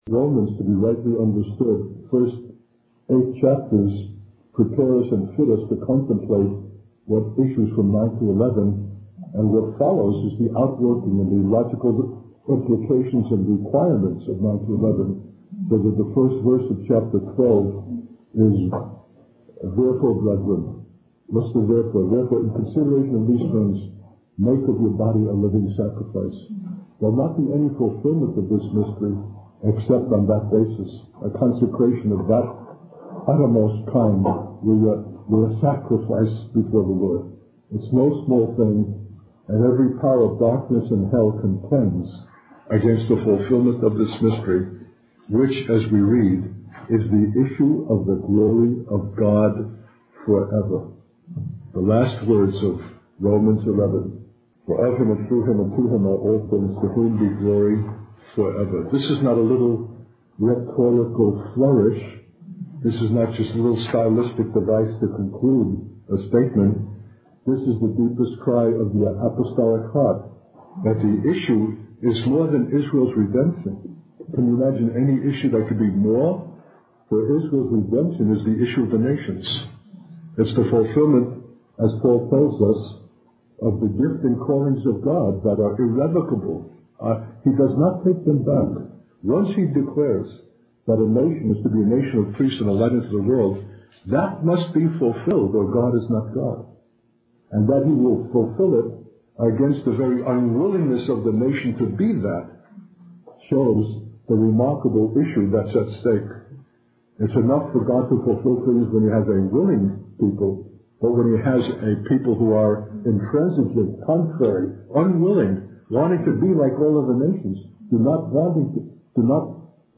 A theme central to all Paul’s apostolic teaching especially insofar as it pertains to the issue of the glory of God forever. A 2004 message.